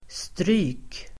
Uttal: [stry:k]